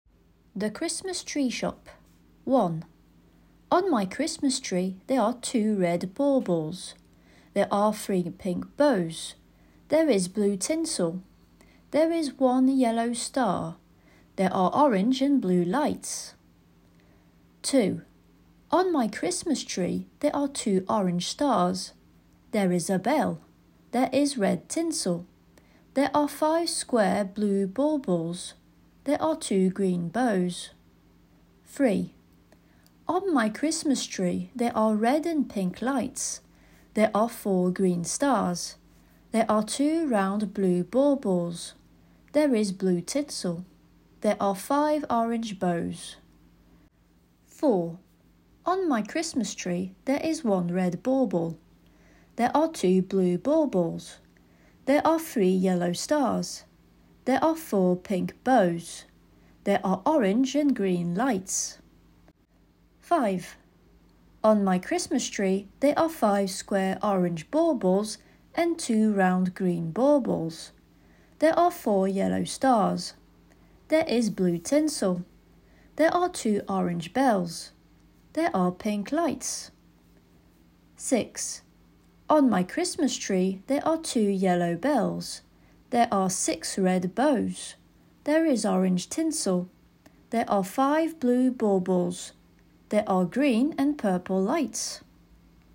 Des fichiers audio avec une voix anglaise native accompagnent l'ensemble conçu prioritairement pour le Cycle 3, mais avec des adaptations possibles pour le Cycle 2.